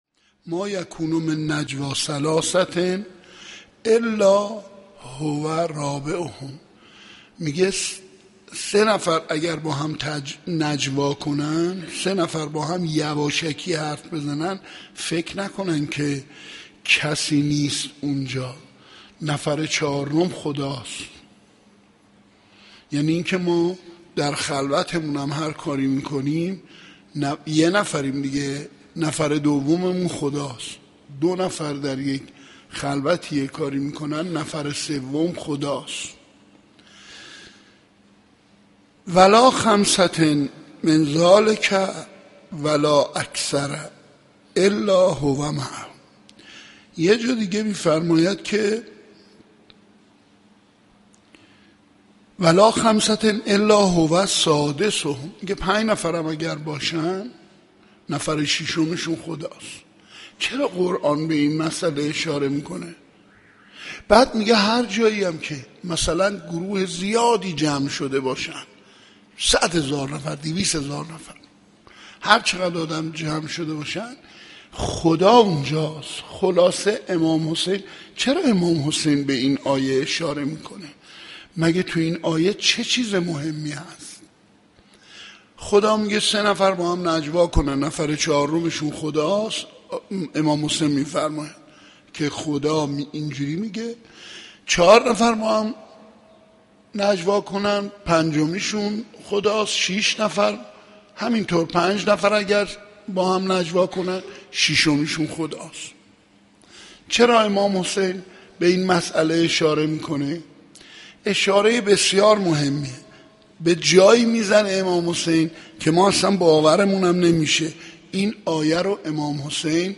حسینیه کربلا - انتخاب الهی - 7 اسفند 1394